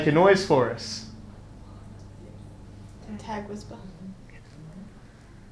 As far as evidence goes, we believe we may have captured an EVP which to us sounds like “get out” in one of the rooms above the hotel bar.
Below is an EVP of what we believe says “get out” you may need earphones and its right after we tag our own whisper.